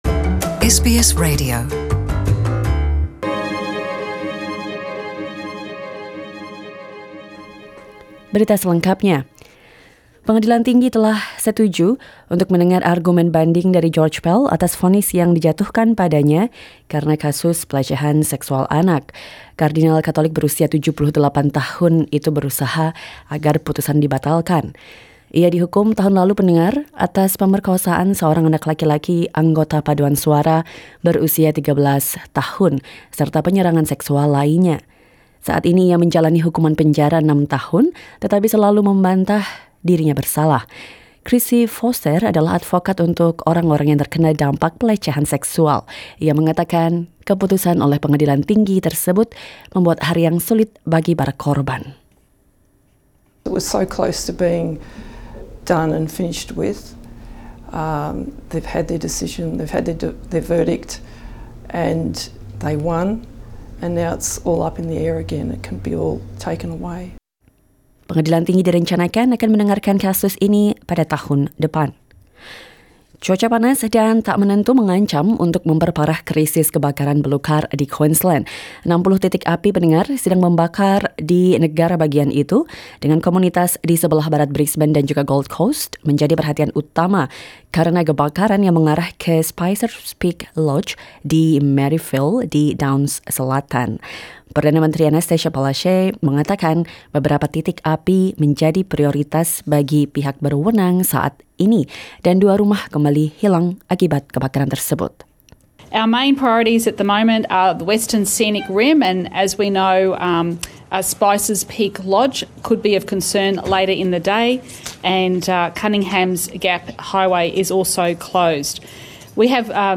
Warta Berita Radio SBS - 13 November 2018